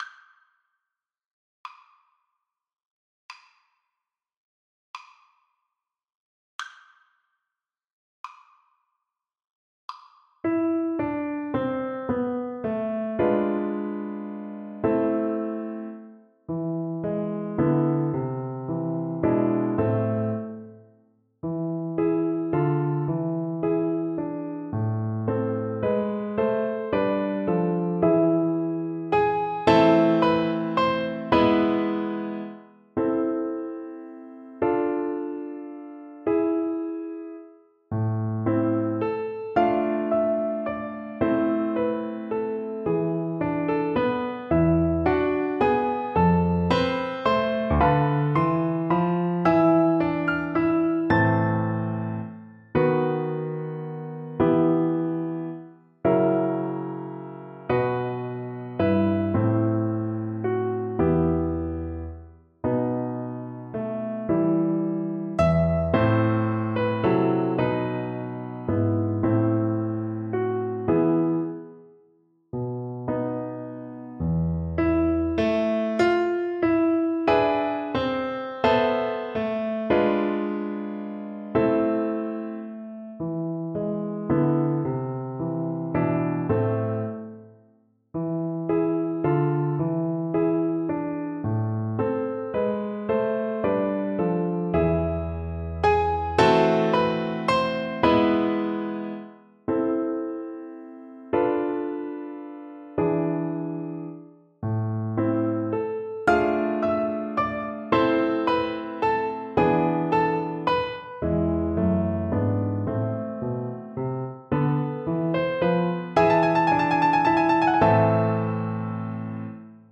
Free Sheet music for Violin
Violin
A minor (Sounding Pitch) (View more A minor Music for Violin )
Allegretto con moto .=56
12/8 (View more 12/8 Music)
E5-A6
Classical (View more Classical Violin Music)